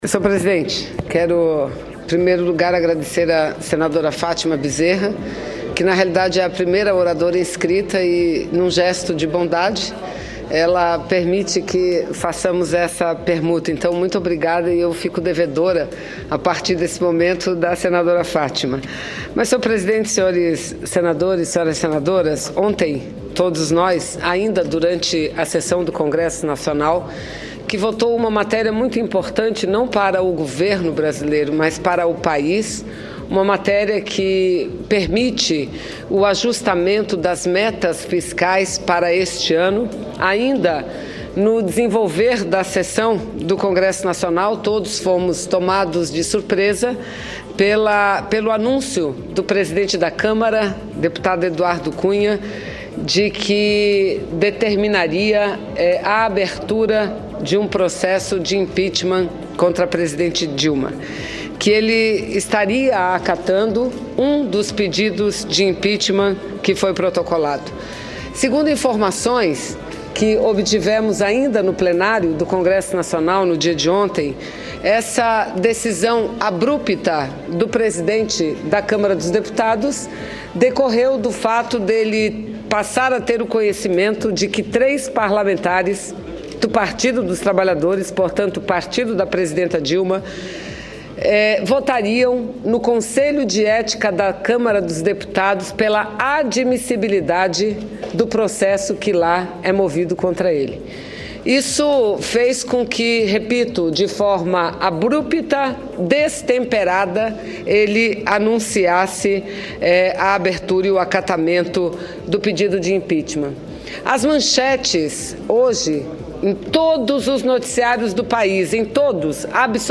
Plenário
Discursos Vanessa Grazziotin critica Cunha por aceitar o pedido de abertura de processo de impeachment RadioAgência Senado 03/12/2015